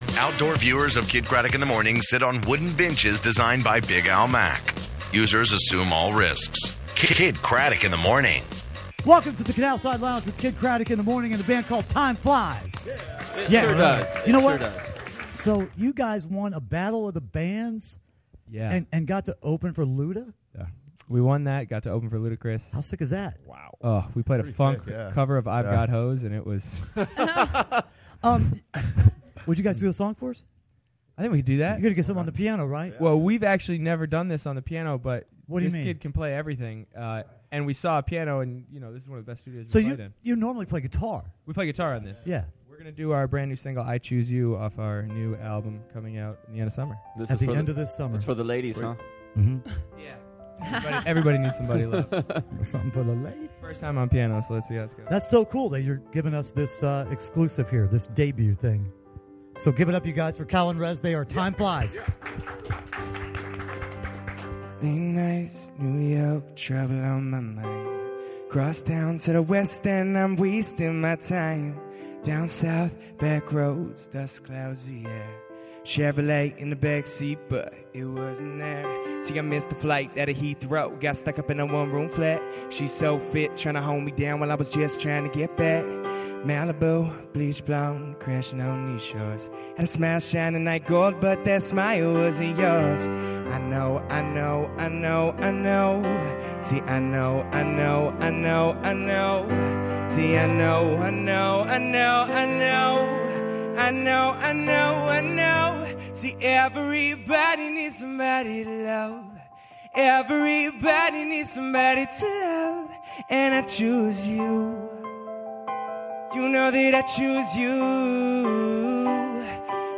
Kidd Kraddick in the Morning interviews Timeflies in the Canalside Lounge!